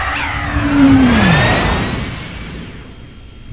home *** CD-ROM | disk | FTP | other *** search / Fish 'n' More 3 / FishMoreVol3.bin / more / audio / vehicles / warp ( .mp3 ) < prev Amiga 8-bit Sampled Voice | 1990-09-10 | 39KB | 1 channel | 11,013 sample rate | 3 seconds
warp.mp3